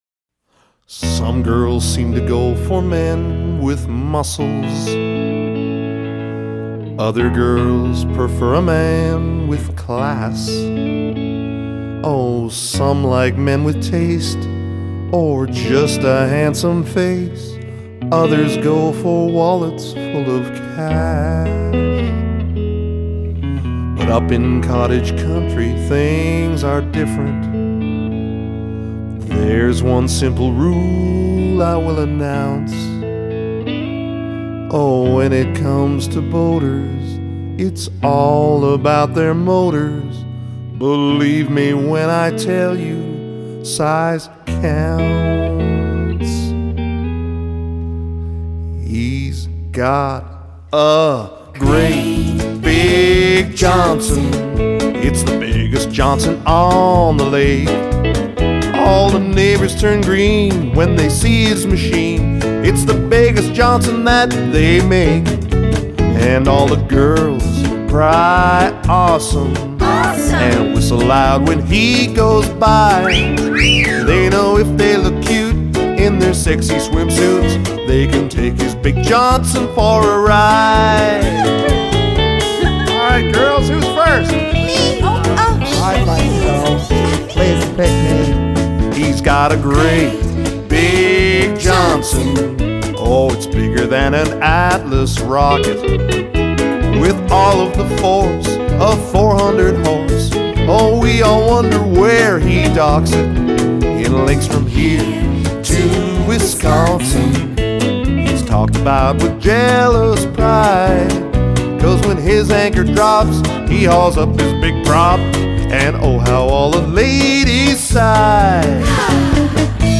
vocals, fiddle
vocals, bass, guitar, and mandolin
vocals, guitar
vocals, bass, mandolin
drums
piano, vocals…